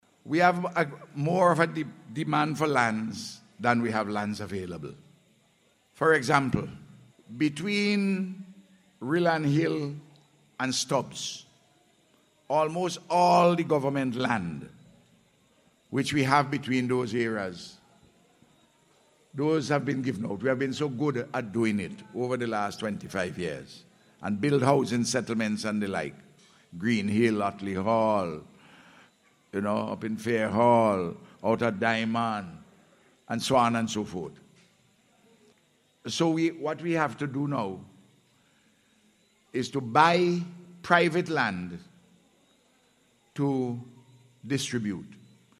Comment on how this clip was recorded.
This was among a range of issues addressed by the Prime Minster, during a Community Consultation held at the Pamelus Burke Primary School yesterday.